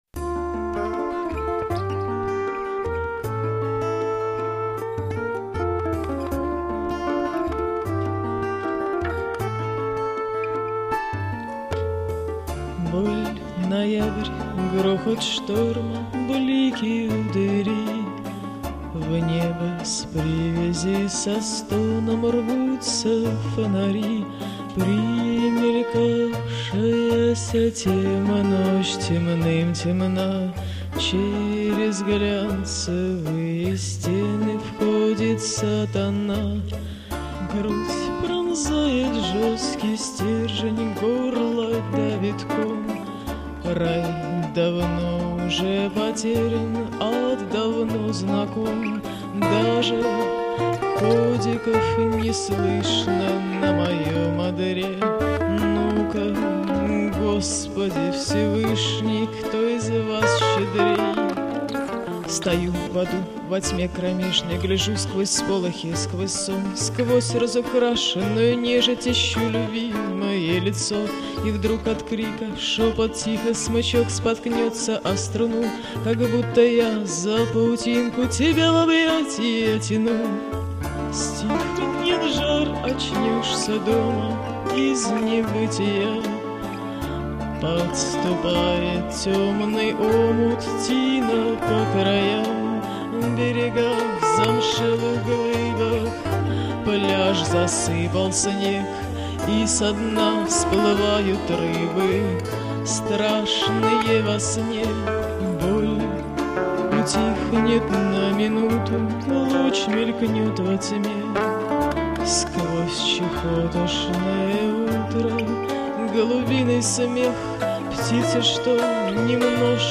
Жанр: авторская песня
Гитара